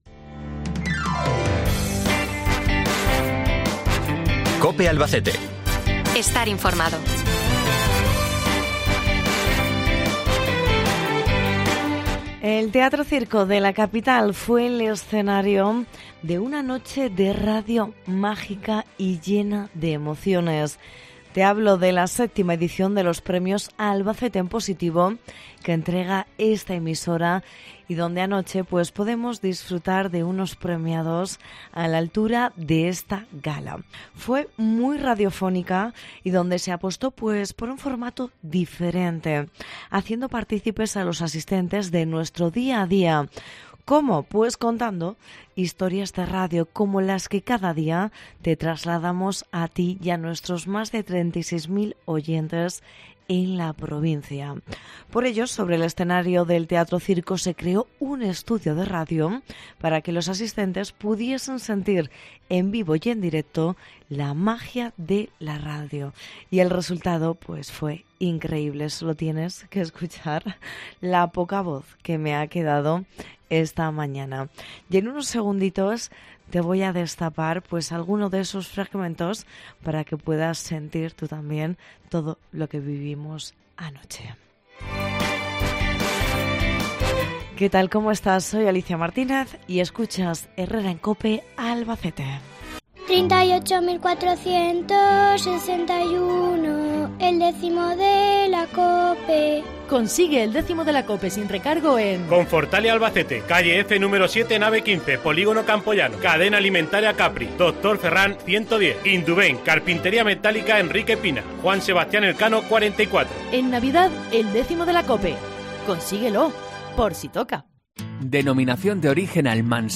El escenario del Teatro Circo fue anoche un gran estudio de radio para la entrega de los premios Albacete en Positivo de COPE
El Teatro Circo fue el escenario de una noche de radio mágica y llena de emociones en la VII edición de los Premios ‘Albacete en Positivo’ que entrega esta emisora.
Sobre el escenario del Teatro Circo se creó estudio de radio para que los asistentes pudiesen sentir en vivo y en directo la magia de la radio, y el resultado fue increíble.